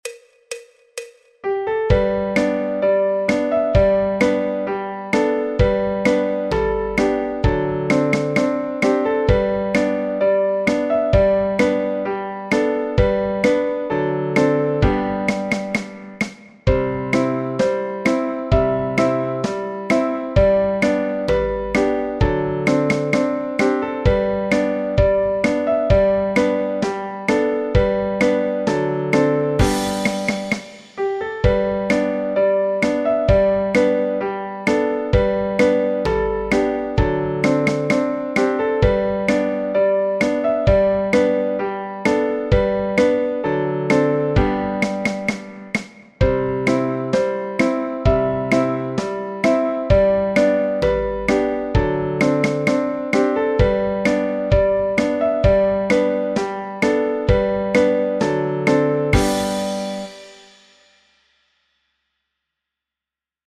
Sol Mayor
Folk, Popular/Tradicional